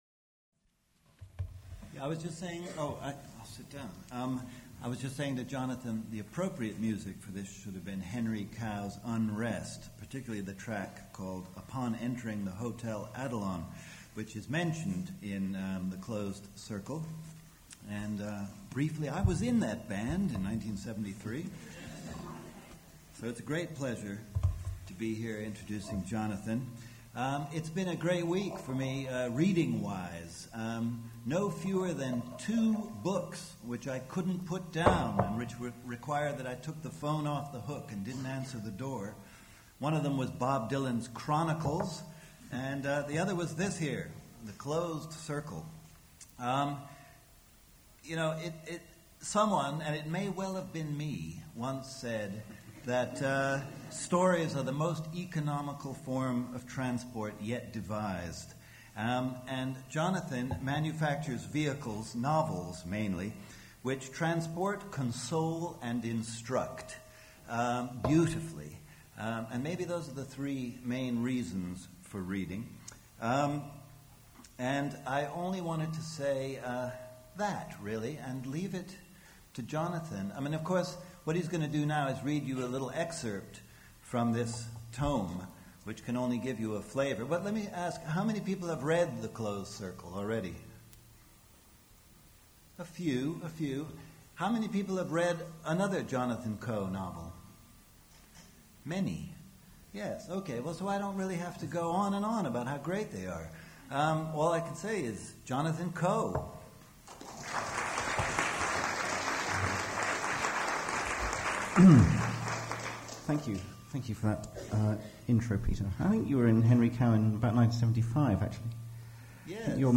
Jonathan Coe reads from his novel The Closed Circle and talks about the political agenda of the novel, the process of "making reality shapely" and imposing patterns on the chaos of life